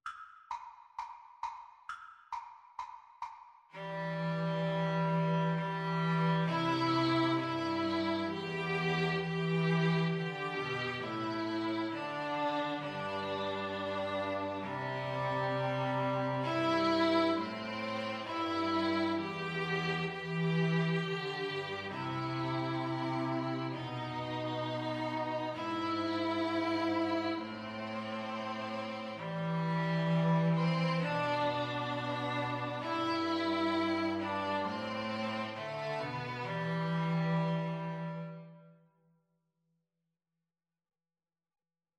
Violin 1Violin 2Cello
4/4 (View more 4/4 Music)